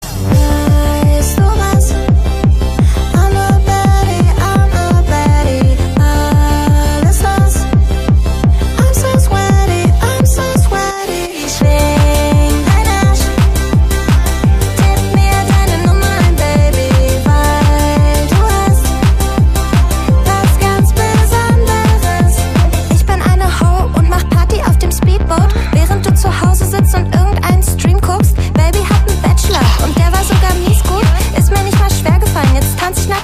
Kategorien: Marimba Remix